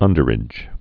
(ŭndər-ĭj)